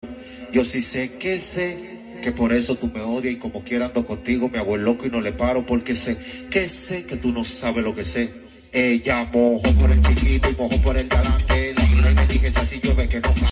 Dembow